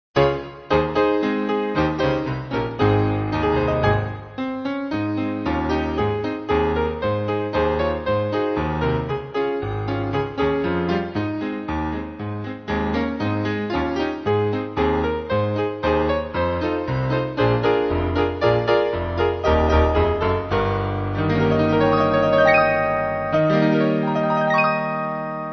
Mainly Piano